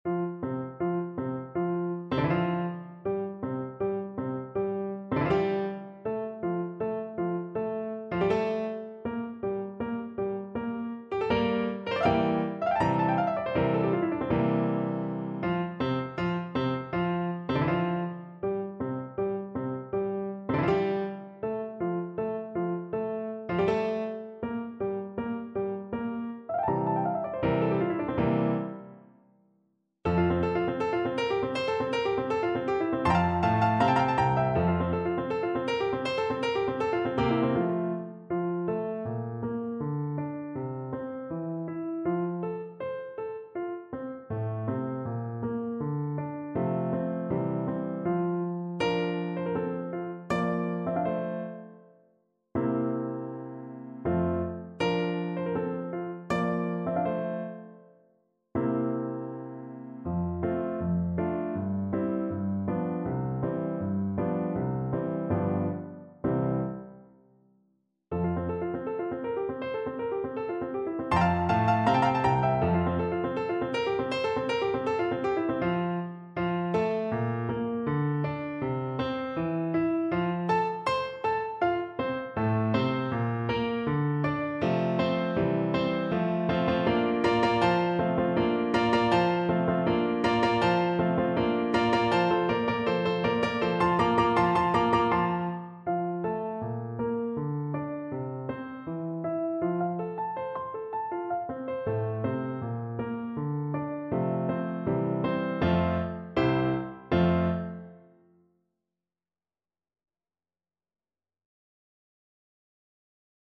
4/4 (View more 4/4 Music)
Molto allegro =160
Classical (View more Classical Flute Music)